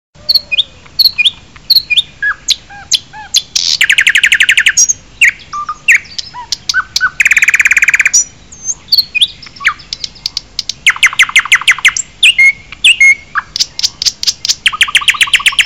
Категория: Звуки